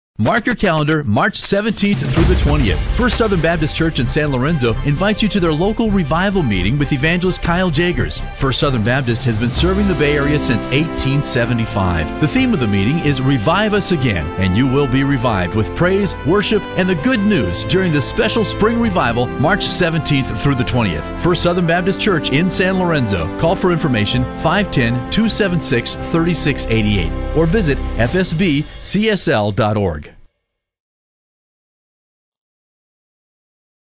If you are want to listen to the 30 second radio advertisement, CLICK HERE .